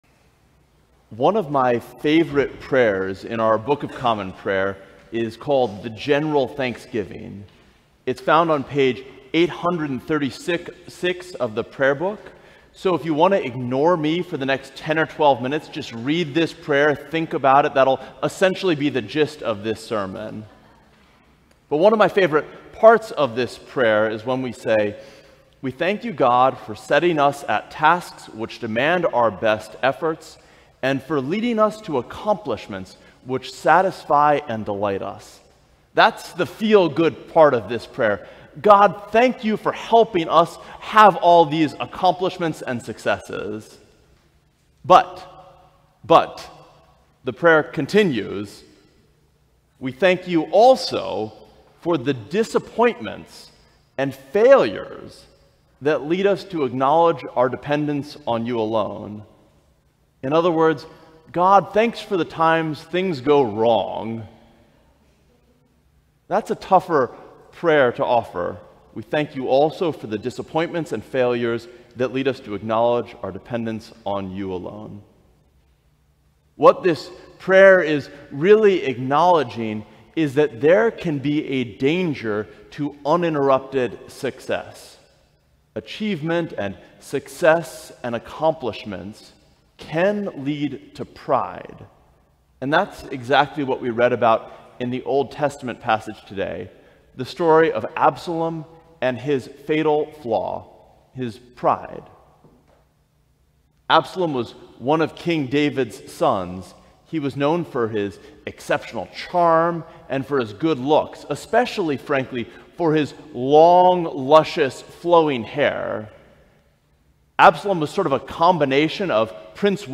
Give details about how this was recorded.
The Twelfth Sunday after Pentecost, Year B We invite you to join us in worship […]